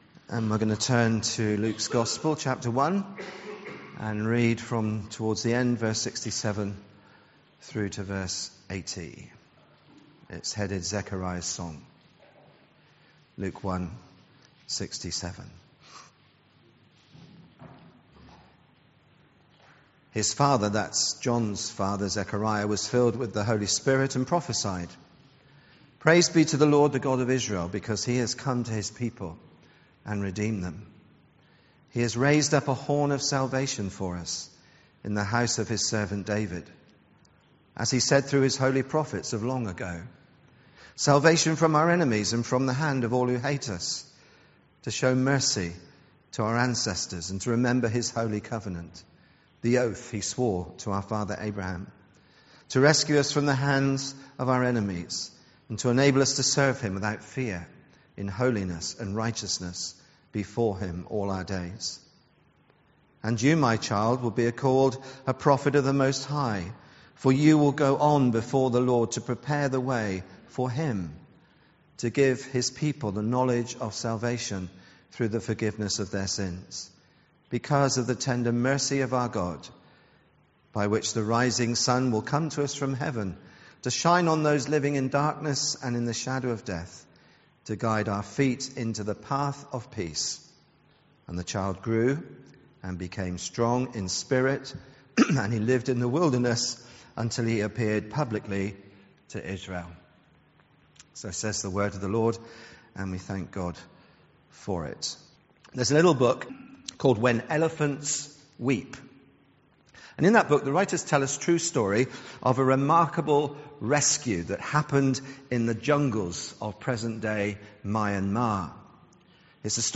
This morning’s sermon, for the second Sunday of Advent, takes the theme of “Zechariah’s Song” from Luke 1 Verse 67-80.